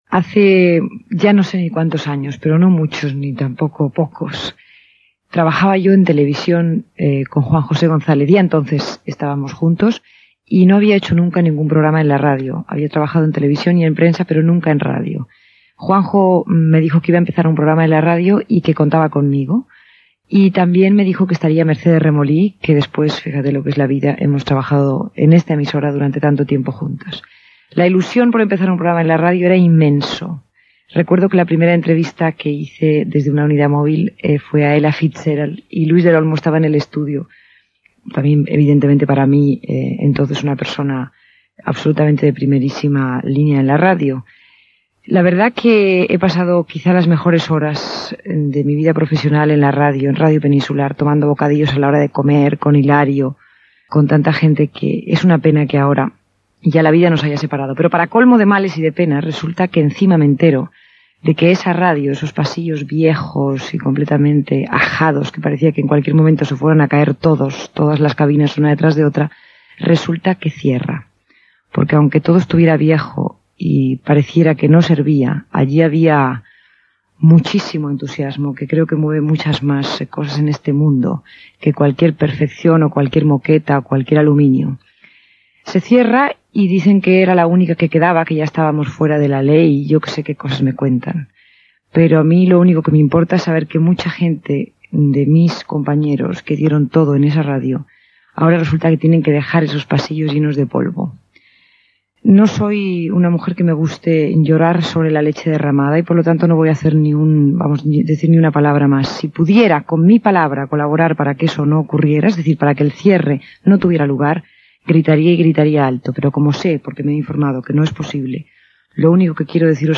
Periodista i presentadora de televisió catalana.
Programa especial pel tancament de Radio 5 (Peninsular), 1984
MILA-Mercedes-tancament-Radio-5-1984.mp3